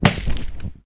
Added gib impact sounds (Droplets compat).